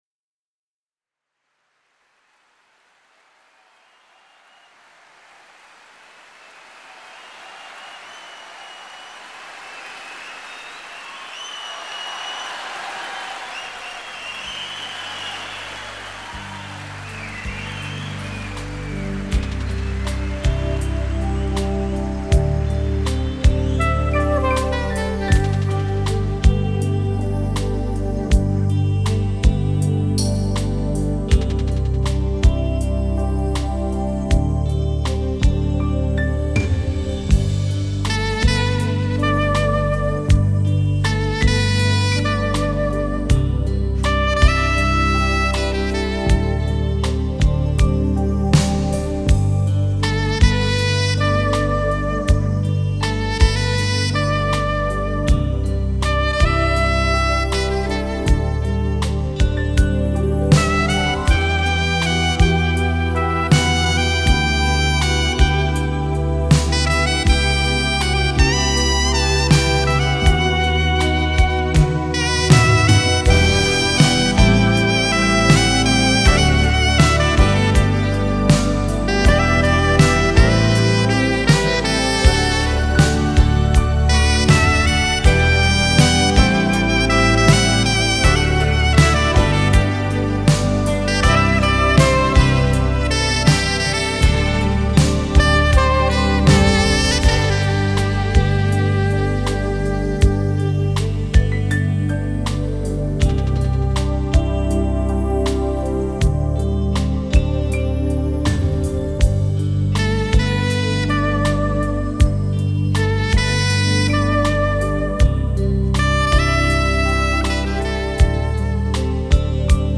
3周前 纯音乐 6